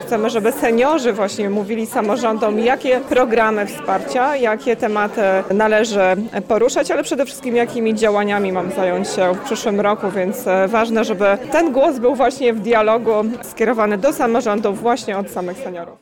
To wyzwanie, na które samorząd województwa odpowiada coraz większą liczbą programów wspierających starszych mieszkańców. O sytuacji seniorów i planach na kolejne lata rozmawiano podczas konferencji w Warszawskiej Operze Kameralnej.
– mówiła Anna Brzezińska, członkini zarządu województwa mazowieckiego.